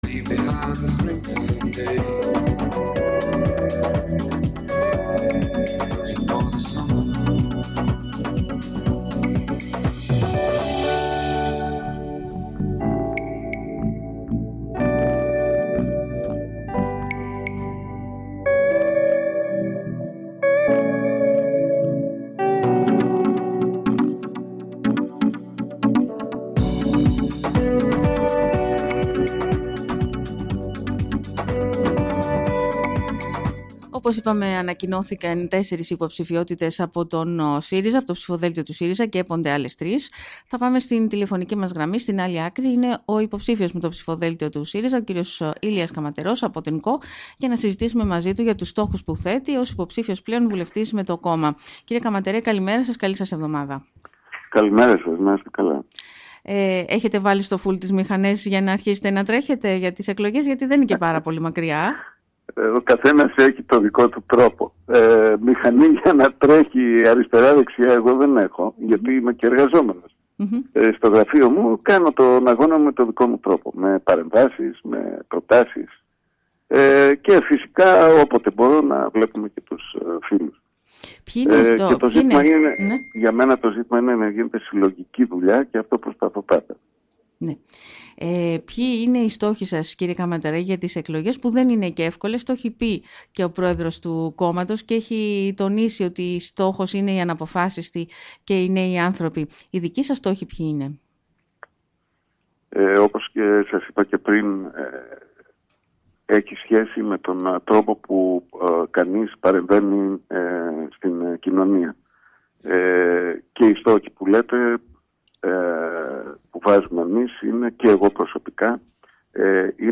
Αναλυτικότερα η  συνέντευξη ξεκίνησε με το ερώτημα αν έχει βάλει στο φουλ τις μηχανές, για τις εθνικές εκλογές που δεν  είναι και πολύ μακριά απάντησε;